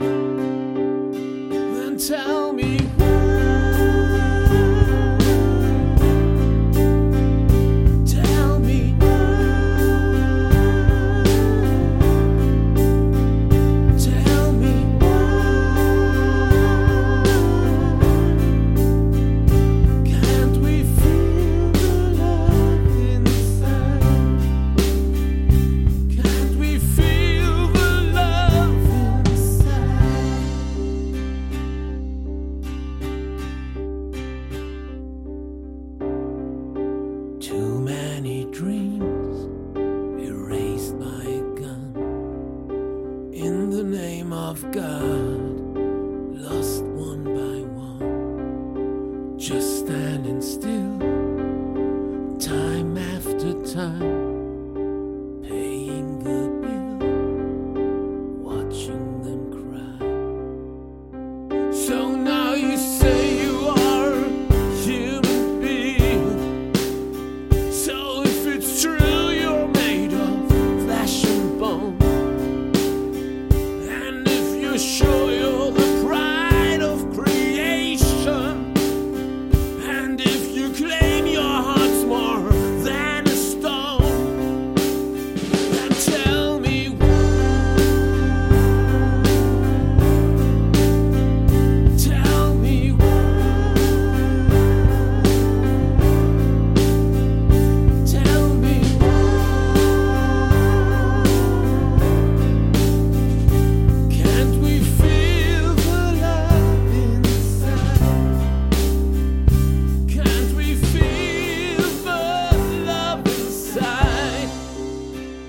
WHY -Rock/Pop Ballade-